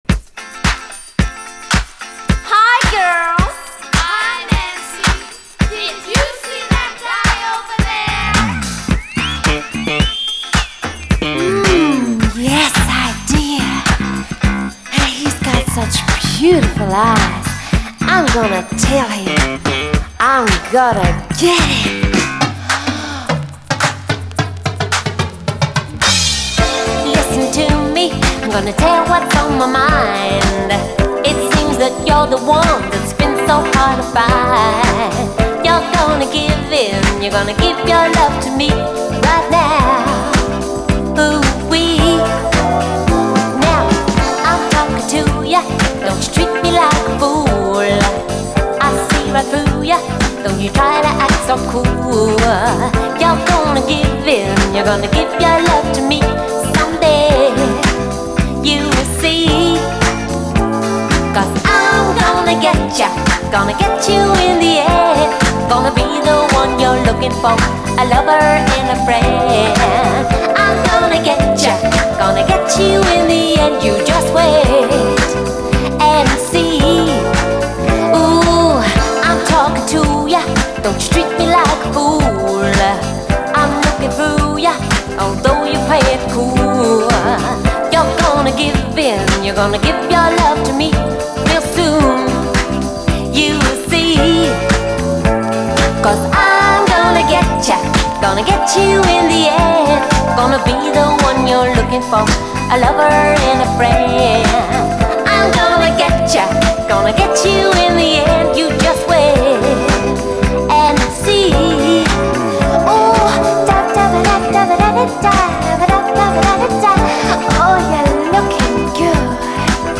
エレピが気持ちいいオランダ産80'Sガーリー・ブギー！
エレピのトロける音色が非常に気持ちよく、ガーリーな雰囲気も可愛いらしい一曲！